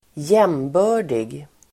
Ladda ner uttalet
Uttal: [²j'em:bö:r_dig]